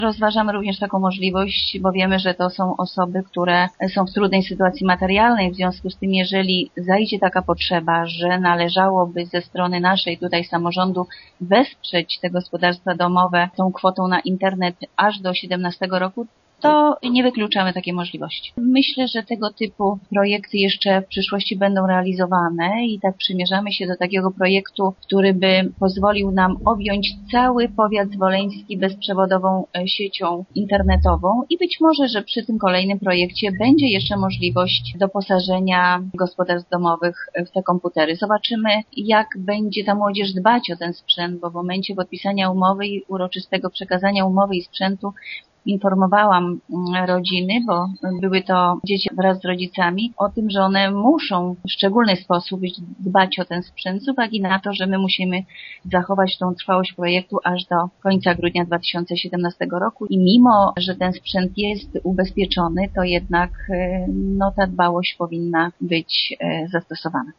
Burmistrz Jaworska nie wyklucza, że samorząd będzie wspierać obdarowanych także po 2012 roku: